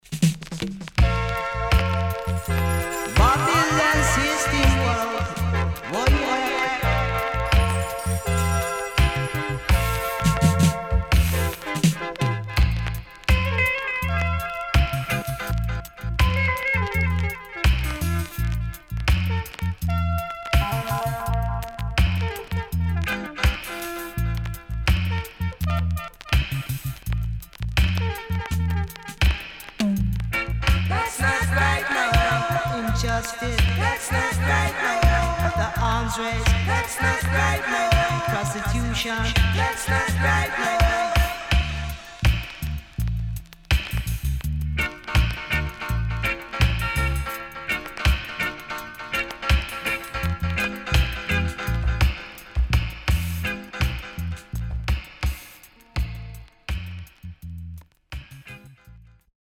HOME > REGGAE / ROOTS  >  KILLER & DEEP  >  RECOMMEND 70's
CONDITION SIDE A:VG(OK)
SIDE A:所々チリノイズがあり、少しプチパチノイズ入ります。